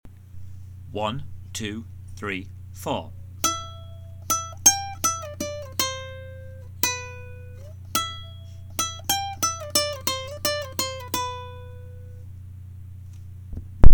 • These are the basic riffs in the same key as Jack plays it.
Riff 2 Sound File